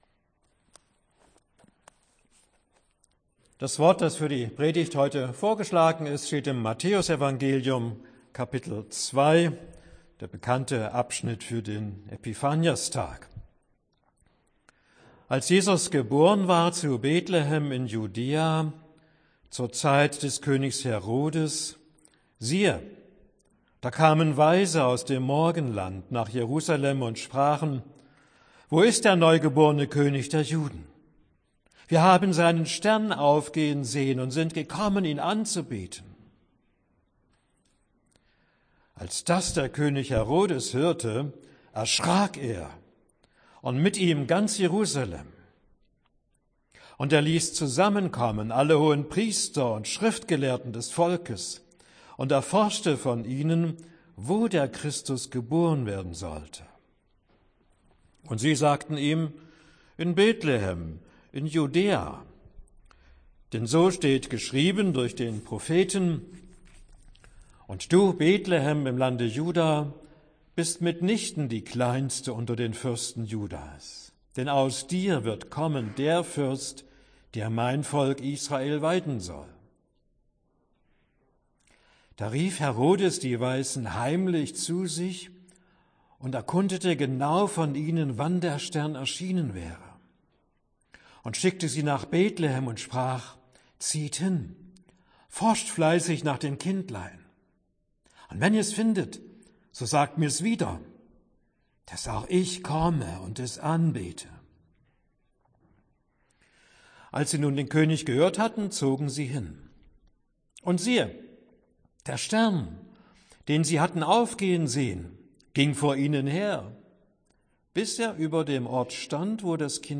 Predigt für Epiphanias Sie können das Manuskript HIER NACHLESEN!